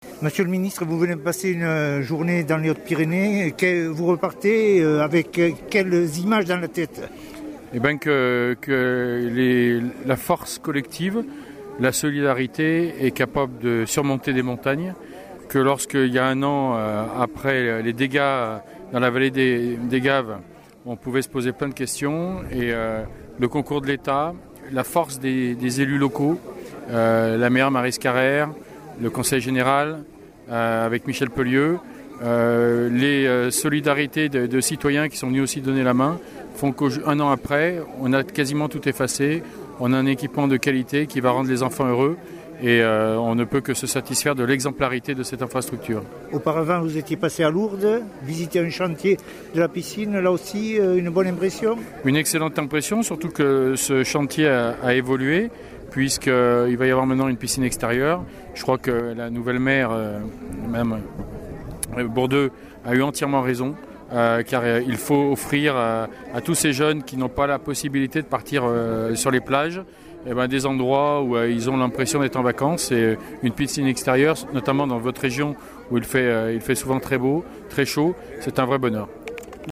Avant de regagner son ministère, nous avons recueilli les impressions de Thierry Braillard de son passage en Bigorre.